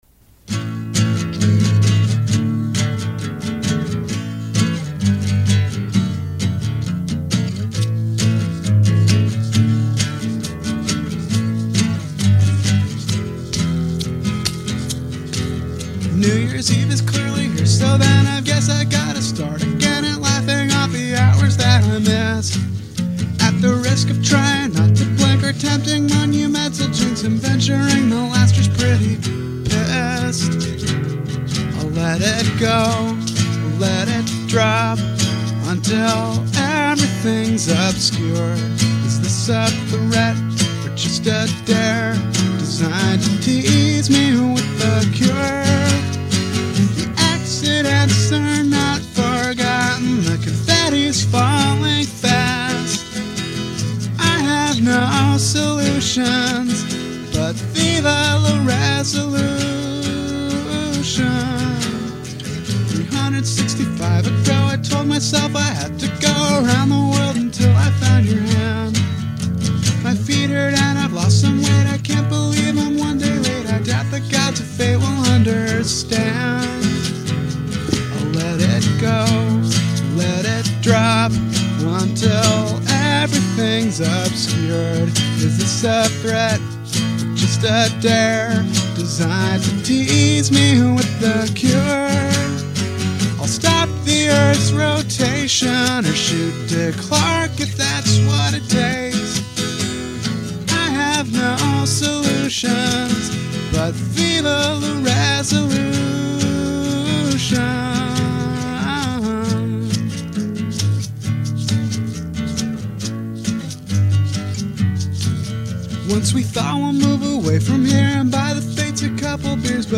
Your Minneapolis-based musician and songwriter
mixing folk, punk and